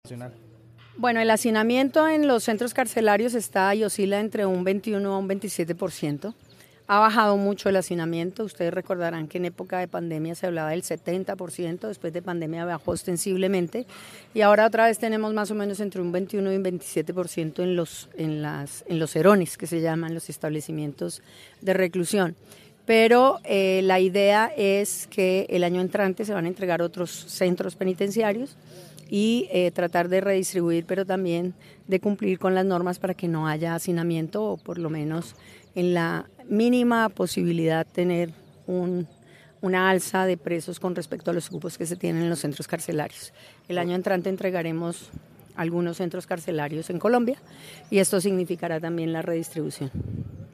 La jefe de la cartera ministerial habló durante su visita a la penitenciaria Peñas Blancas de Calarcá, Quindío
Angela María Buitrago, Ministra de Justicia